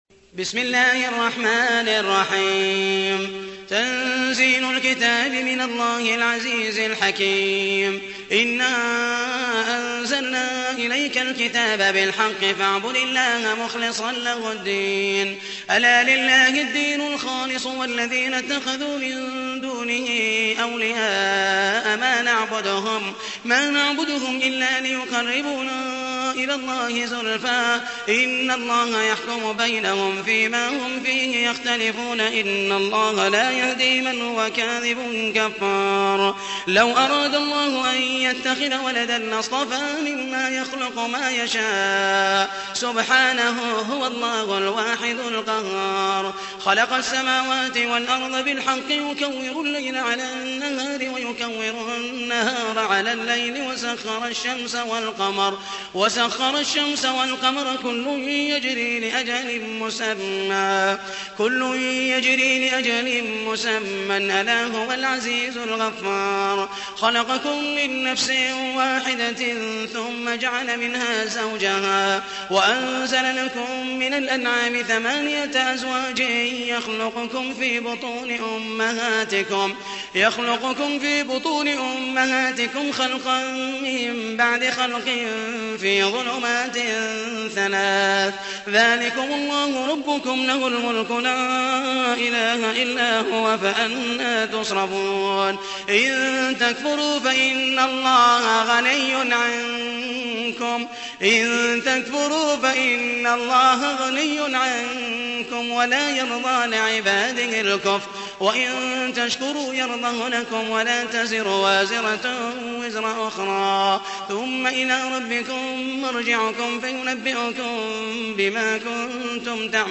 تحميل : 39. سورة الزمر / القارئ محمد المحيسني / القرآن الكريم / موقع يا حسين